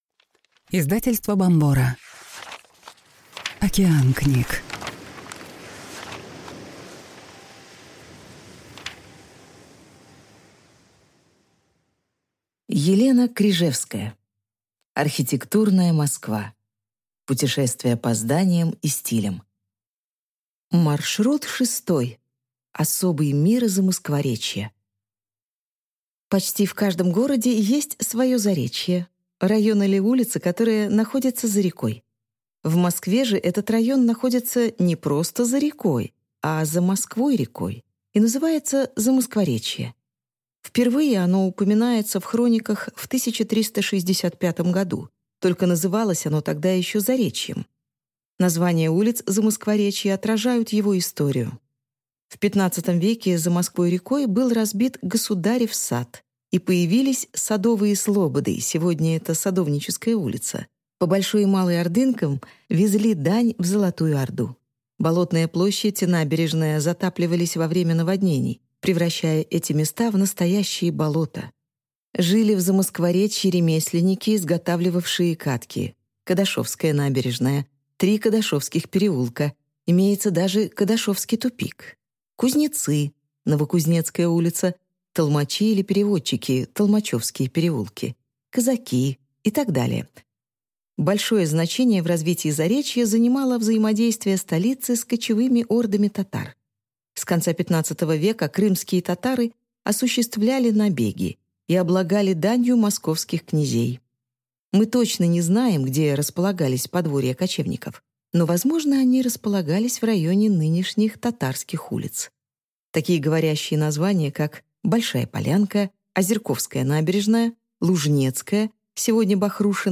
Аудиокнига Особый мир Замоскворечья | Библиотека аудиокниг